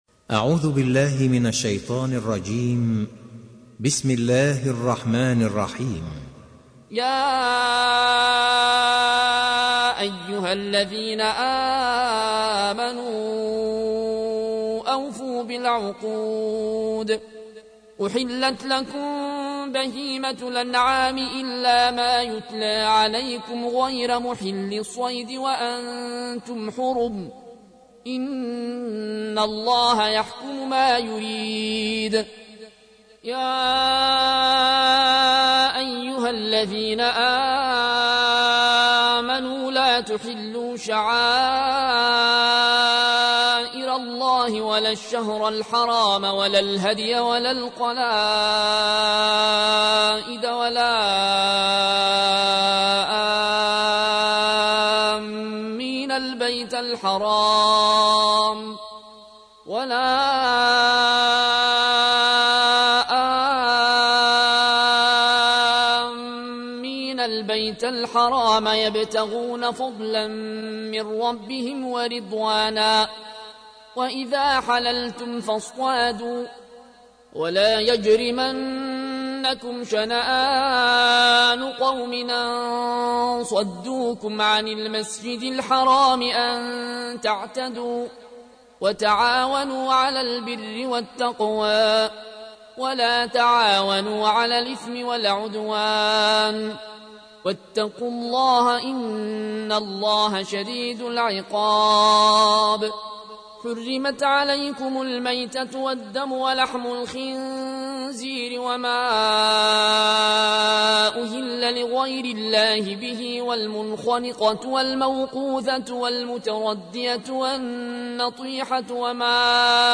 تحميل : 5. سورة المائدة / القارئ العيون الكوشي / القرآن الكريم / موقع يا حسين